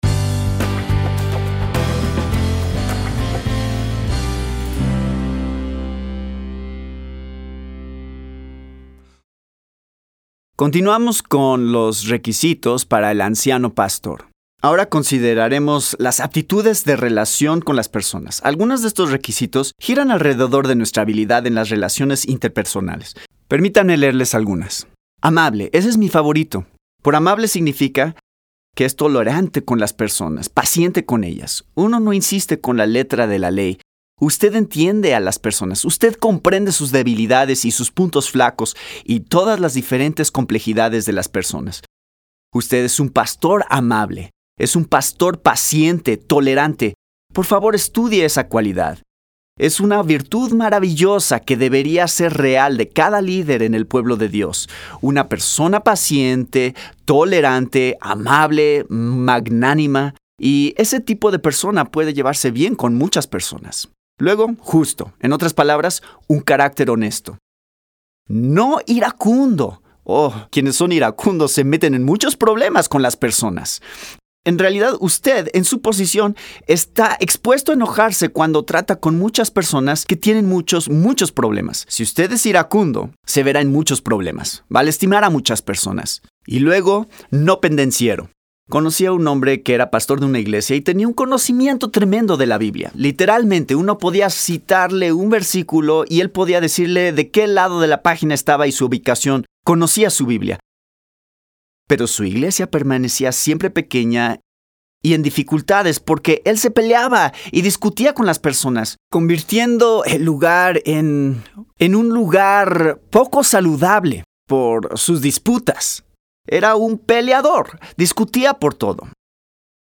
El orador continúa explicando los requisitos bíblicos para los ancianos. Los ancianos deben poseer buenas aptitudes de relación con las personas, ser hospedadores, y tener integridad personal.